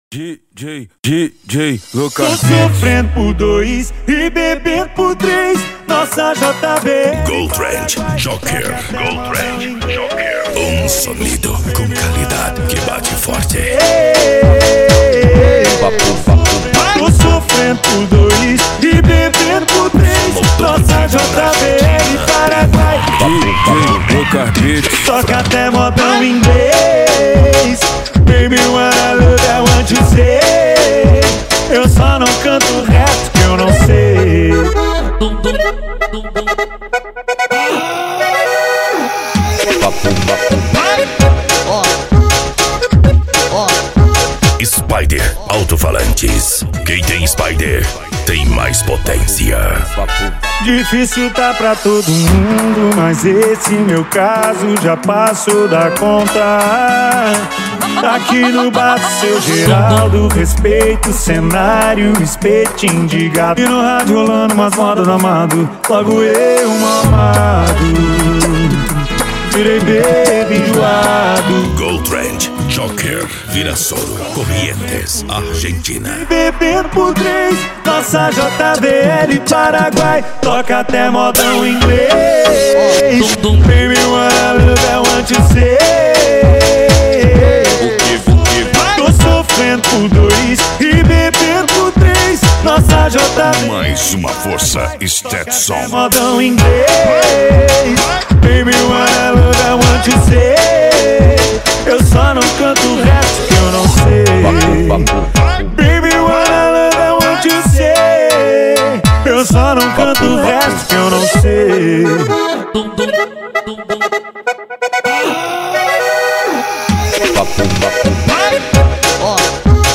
Arrocha
Funk
Remix